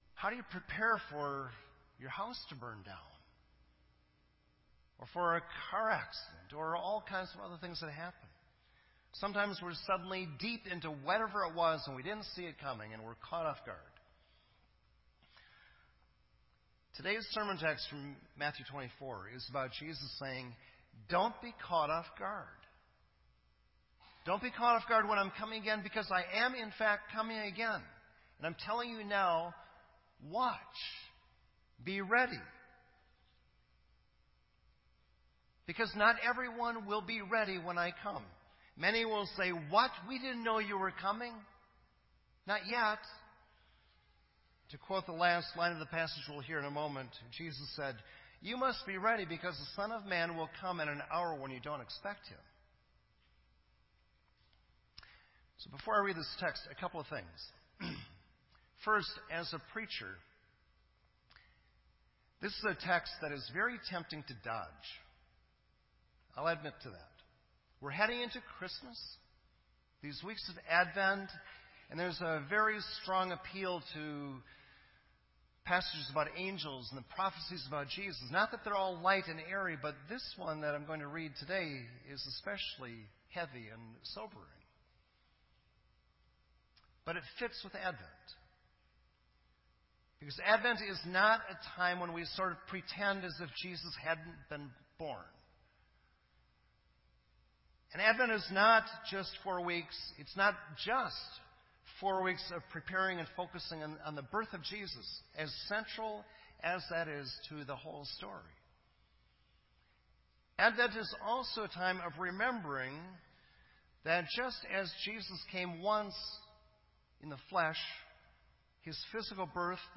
This entry was posted in Sermon Audio on November 28